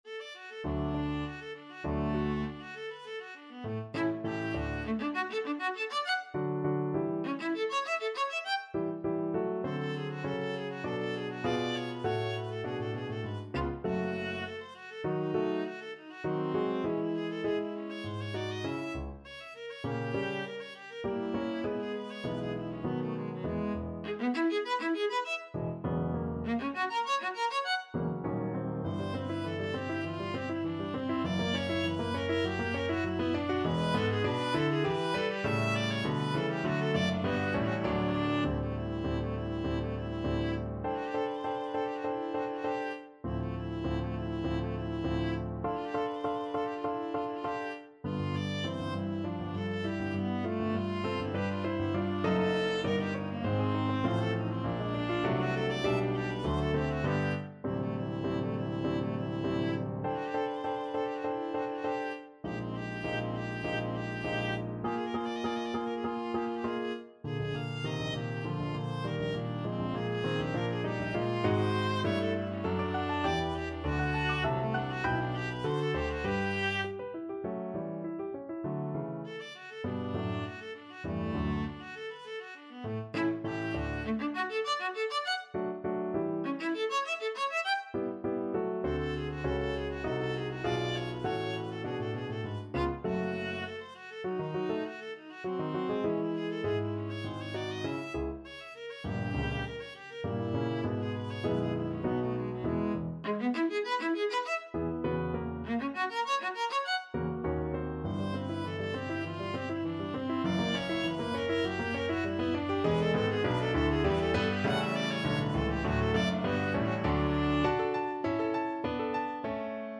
Classical Saint-Saëns, Camille Clarinet Sonata, Op. 167, 2nd Movement, Allegro Animato Viola version
Free Sheet music for Viola
D major (Sounding Pitch) (View more D major Music for Viola )
D4-G6
~ = 200 Allegro Animato (View more music marked Allegro)
2/2 (View more 2/2 Music)
Classical (View more Classical Viola Music)